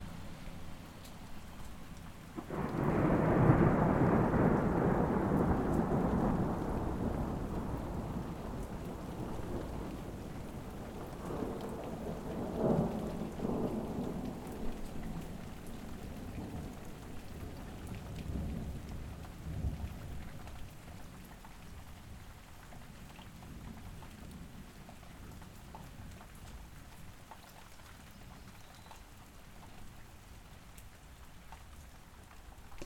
thunder_base.mp3